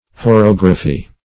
Search Result for " horography" : The Collaborative International Dictionary of English v.0.48: Horography \Ho*rog"ra*phy\, n. [Gr.